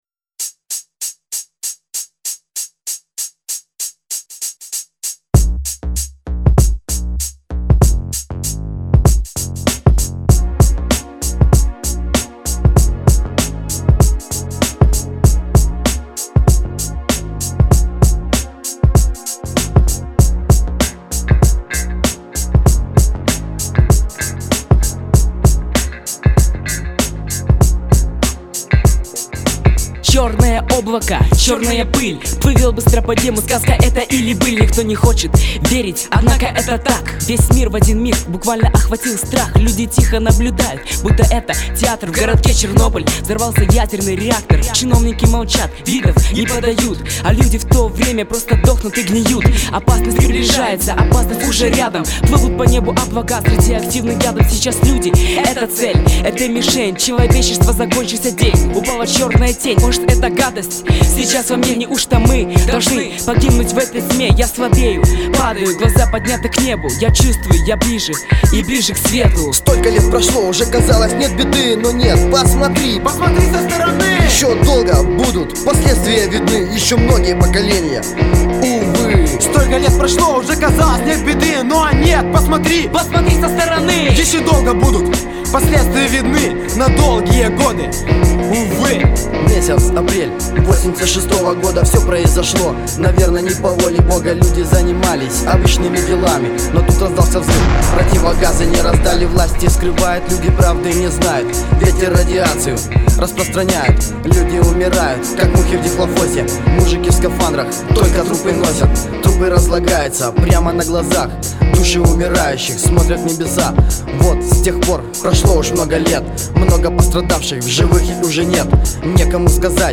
Реп сталкер [9]
Песня сталкер (реп) о Чернобыле.